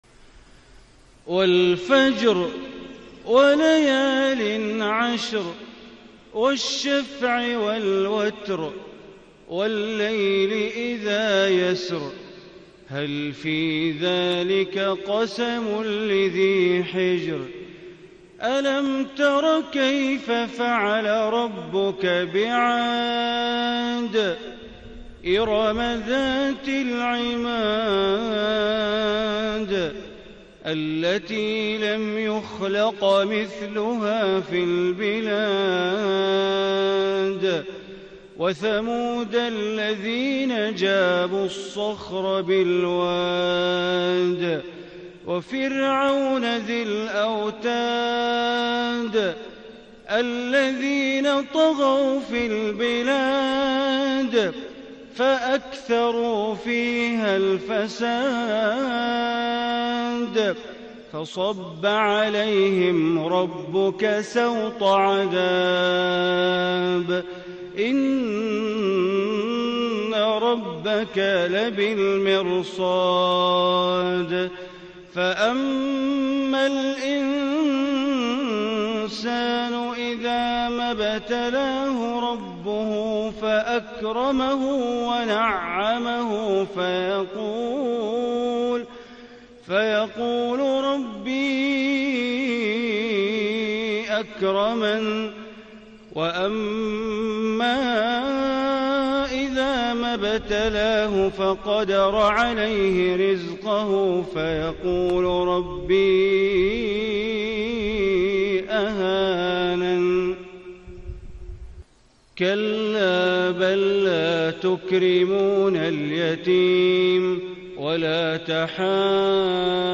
سورة الفجر > مصحف الحرم المكي > المصحف - تلاوات بندر بليلة